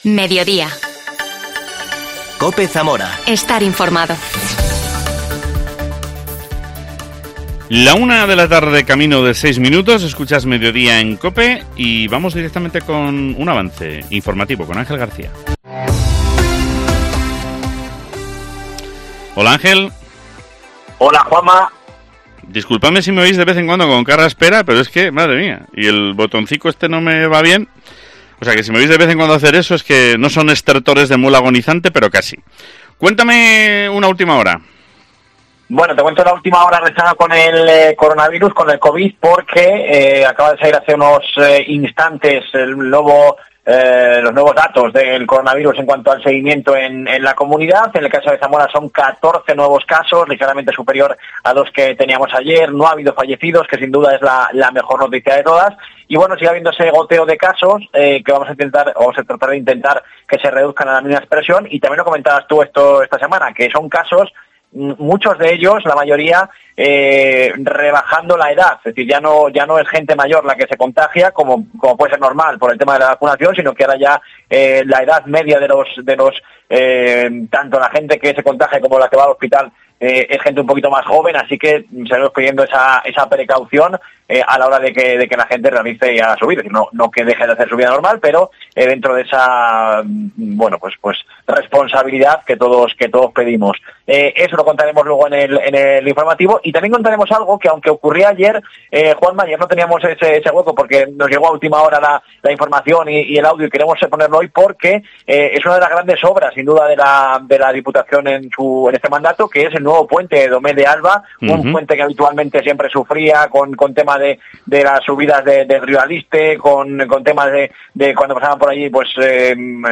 AUDIO: Hablamos con el exjefe provincial de Tráfico, Eduardo Polo, quien deja su puesto en Zamora para trabajar en Palencia.